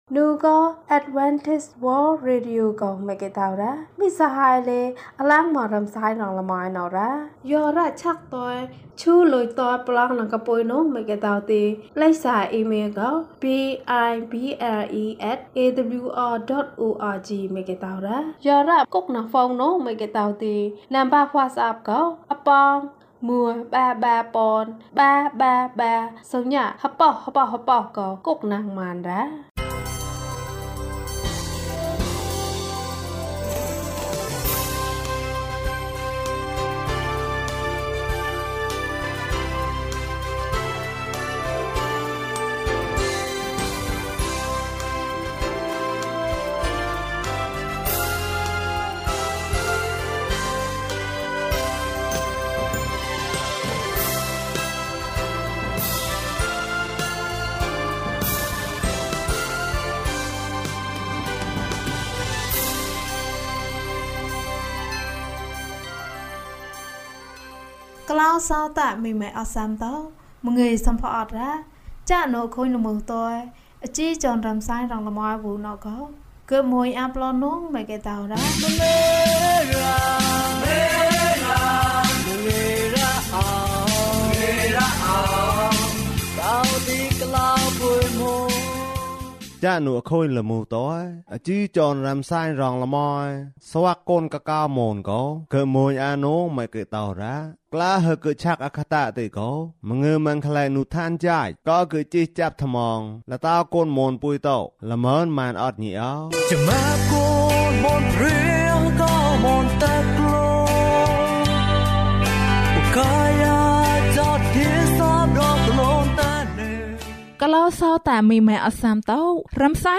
ဘဝသစ်။၀၁ ကျန်းမာခြင်းအကြောင်းအရာ။ ဓမ္မသီချင်း။ တရားဒေသနာ။